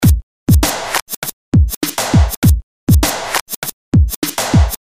标签： 100 bpm RnB Loops Drum Loops 413.48 KB wav Key : Unknown
声道单声道